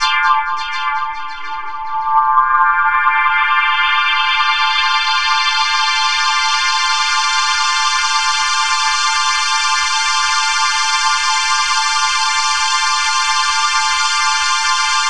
Pad Loop_.wav